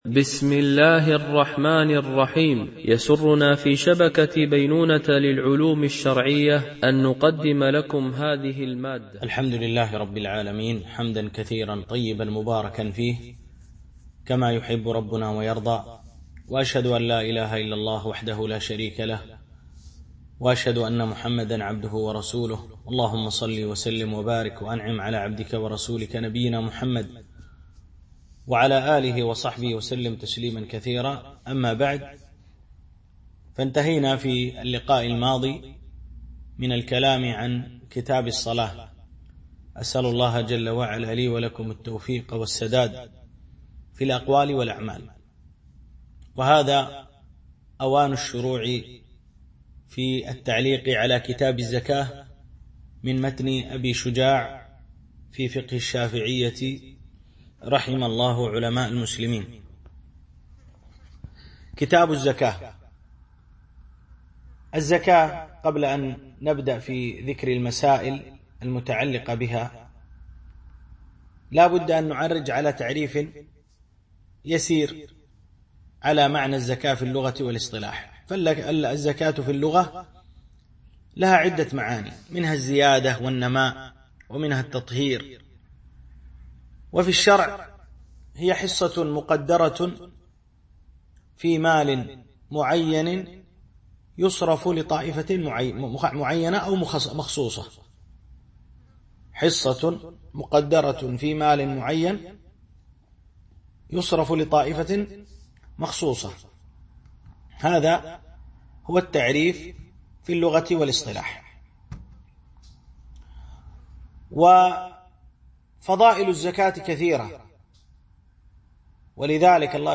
شرح متن أبي شجاع في الفقه الشافعي ـ الدرس 18